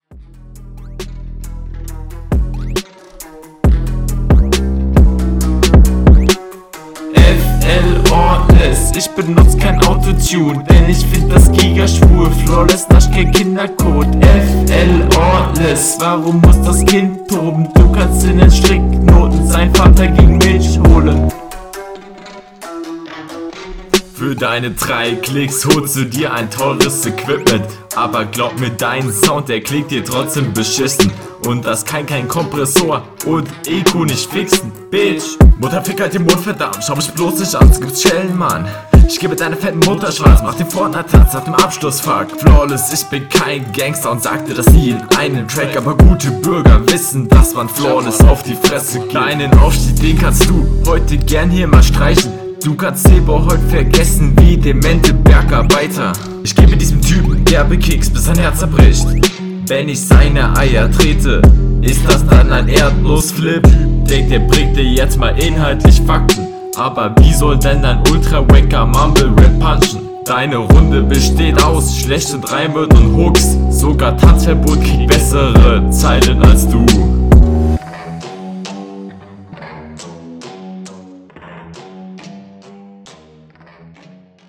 Hook klingt nicht so toll.